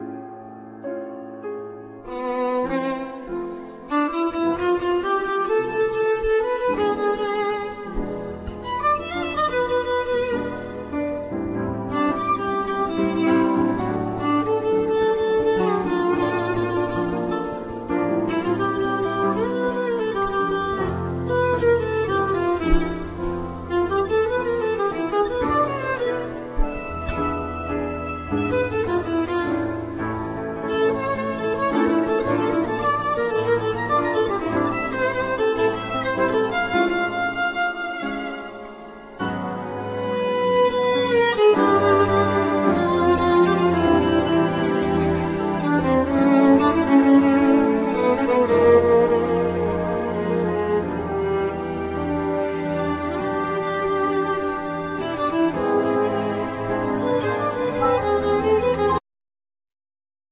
Violin
Keyboards
Drums
Bass
El.guitar
Sopranosaxophne